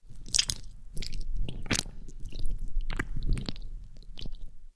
звук ковыряния в мякоти томата